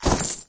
SE_SYS_Item_Coin.wav